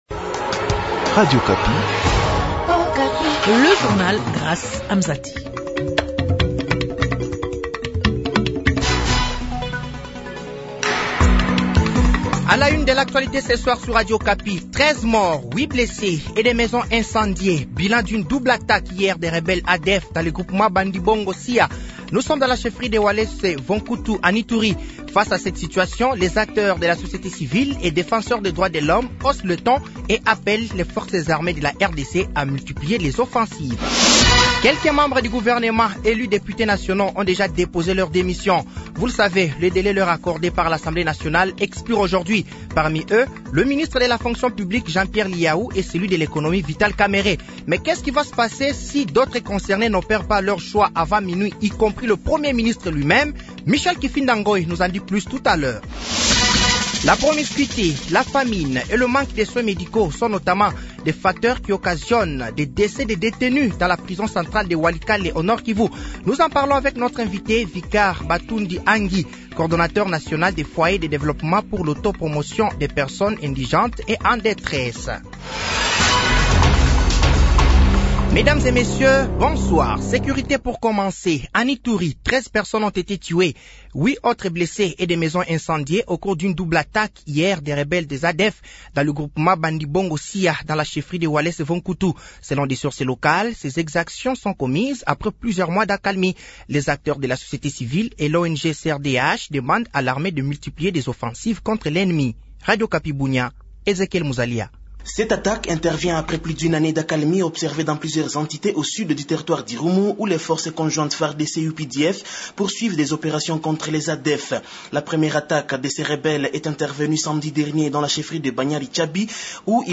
Journal français de 18h de ce mardi 20 février 2024